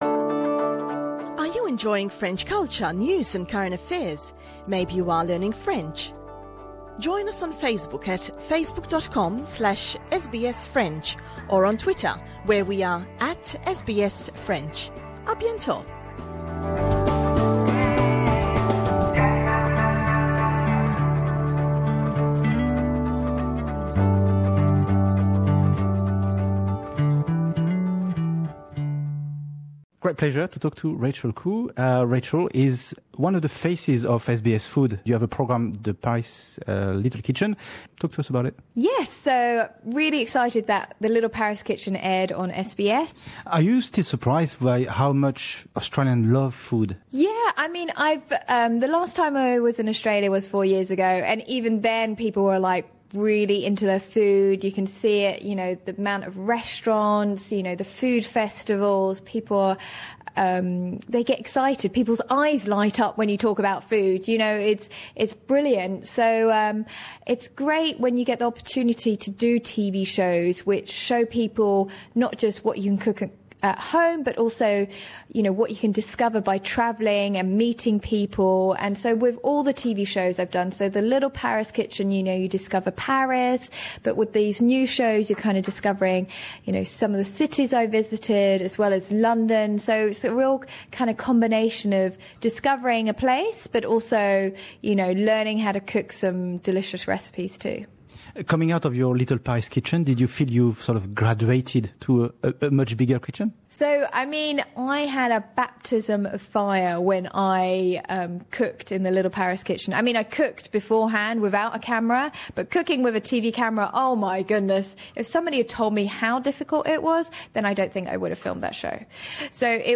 British food sensation Rachel Khoo immerses herself in the world-renowned foodie capital of Australia as Rachel Khoo's Kitchen Notebook Melbourne repeats on SBS . Interview originally recorded in July 2015